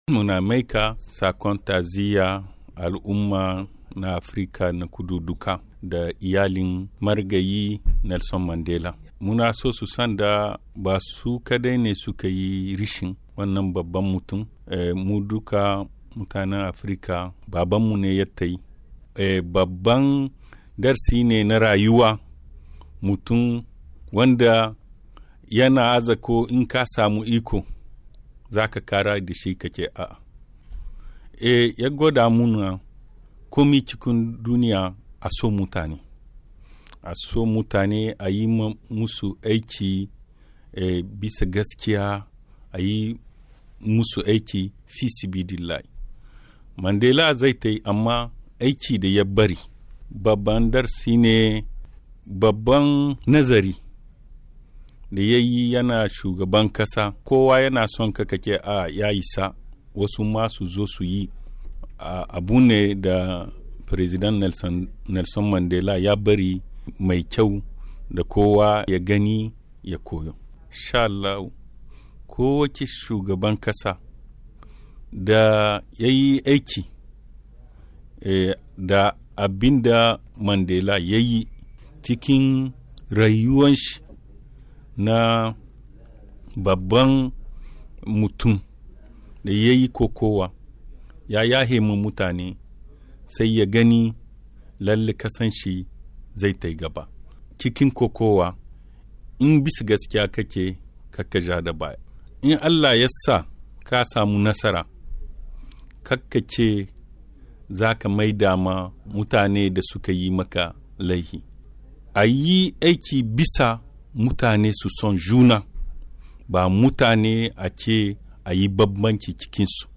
Kakakin gwamnatin kasar ta Nijer kuma ministan shari'a Morou Amadou ne ya isar da sakon hukumomin kasar a wani taron manema labarai da ya yi jumma'a a birnin Niamey